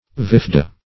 Search Result for " vifda" : The Collaborative International Dictionary of English v.0.48: Vifda \Vif"da\, n. In the Orkney and Shetland Islands, beef and mutton hung and dried, but not salted.